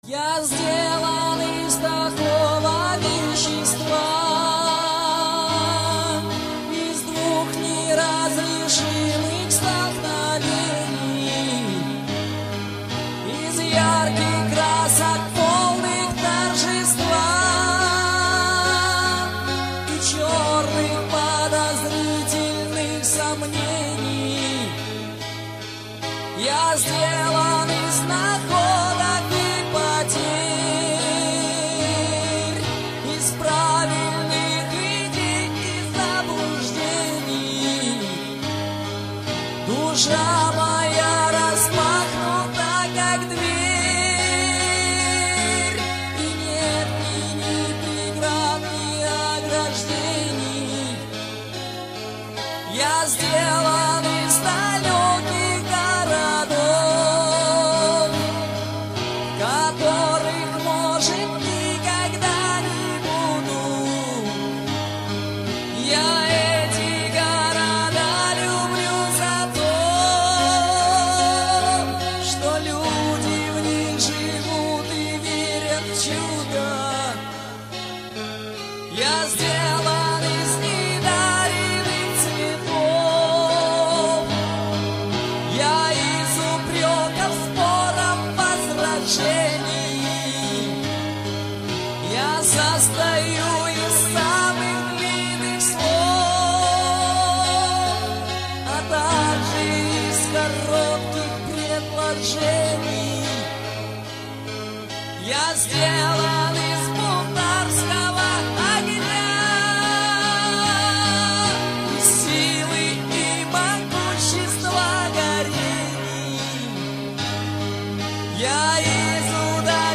• Качество: 320, Stereo
мужской вокал
душевные
грустные
спокойные
пианино
vocal